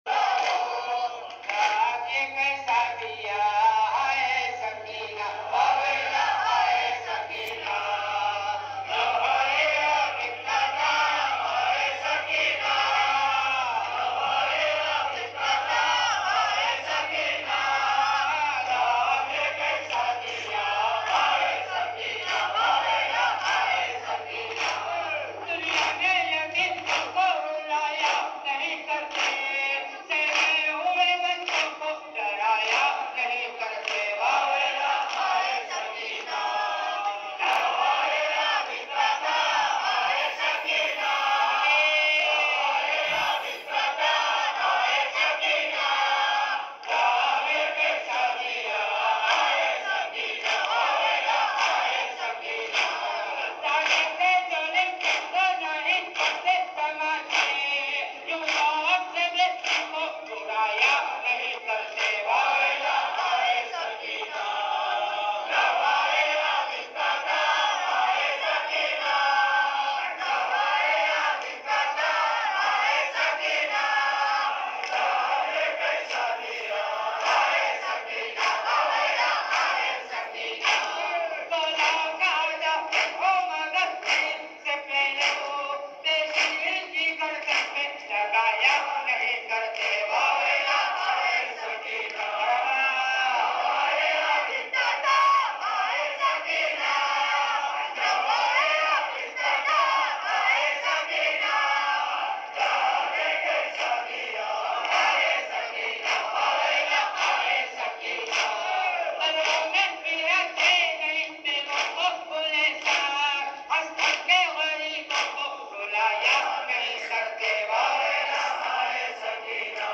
Nawha Ye Abid Ka Tha Haye Sakina (Daagh Ye Kaisa Diya)